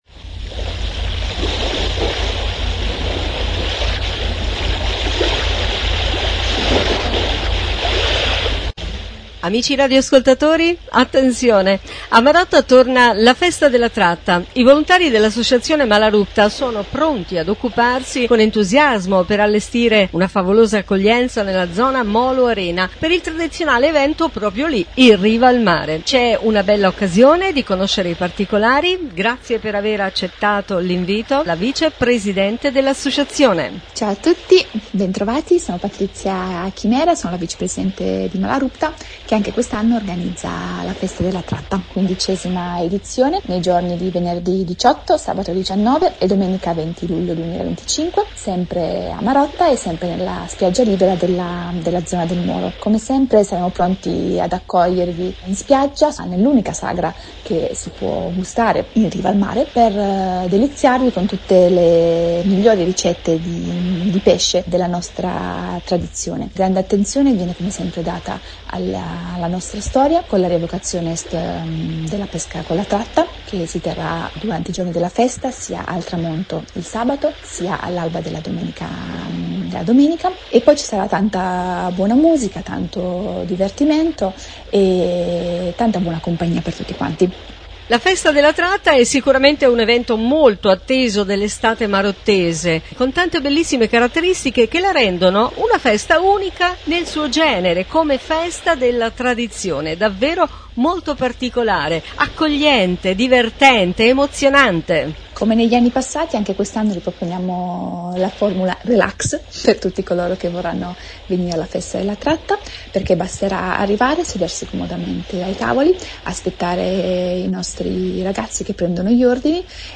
intervista-LA-TRATTA-2025-SITO.mp3